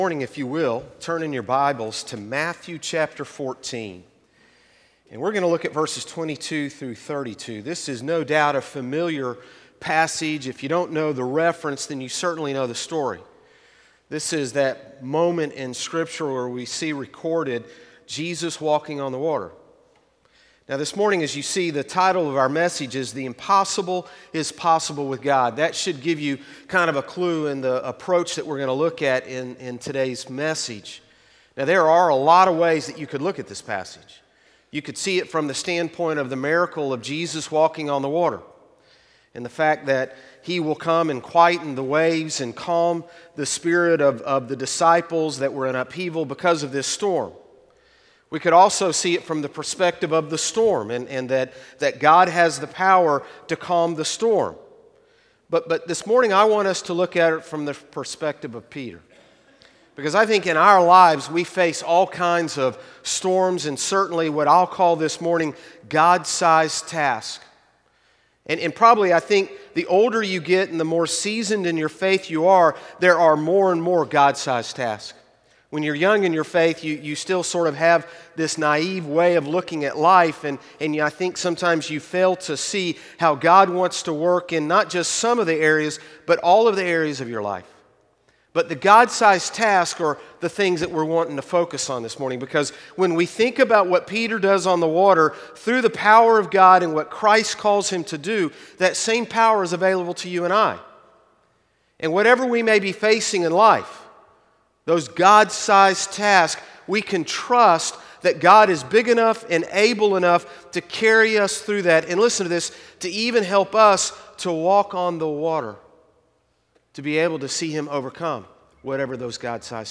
Morning Service - The Impossible Is Possible With God | Concord Baptist Church
Sermons - Concord Baptist Church